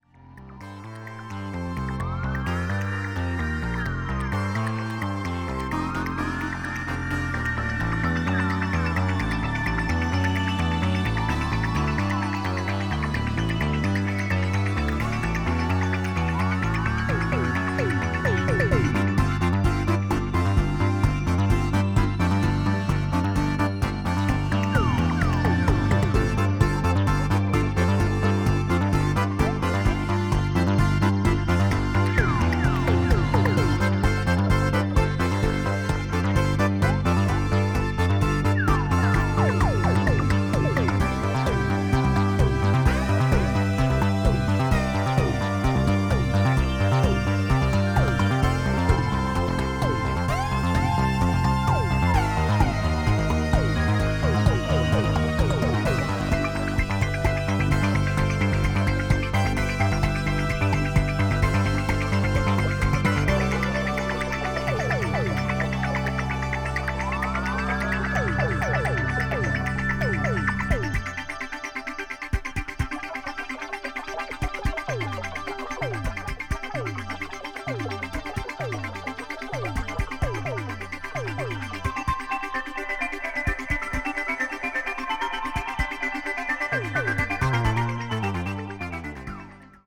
とにかく音が良いです。
electronic   new age   oriental   synthesizer